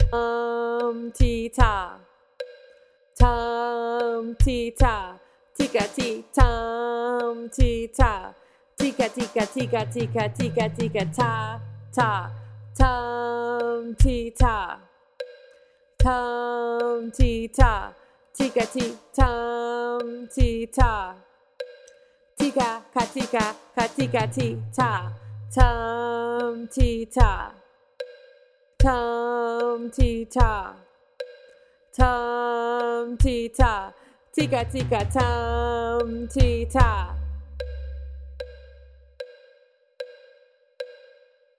In this example you will use the Kodály Method to read through a rock rhythm that uses a combination of simple rhythms and syncopation.
Rock Rhythm: Spoken with Drum
TR6-ROCK-RHYTHM-WITH-DRUM.mp3